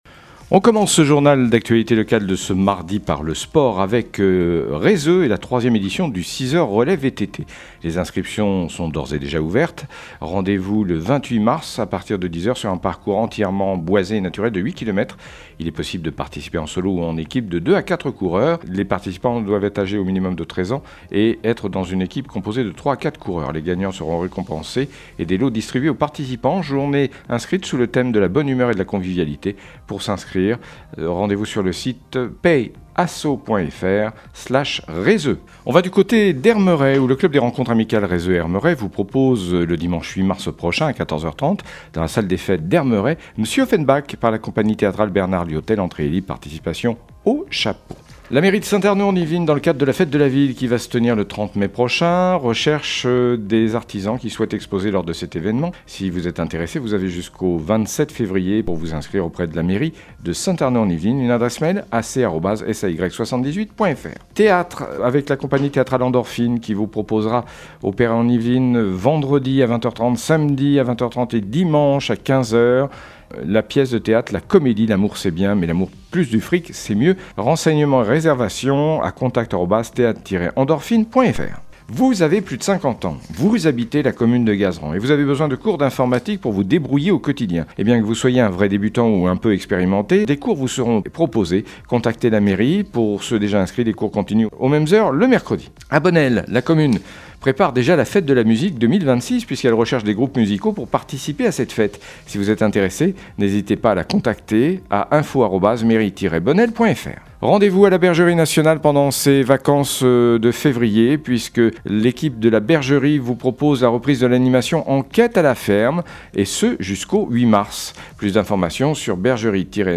Le journal local
24.02-flash-local-matin.mp3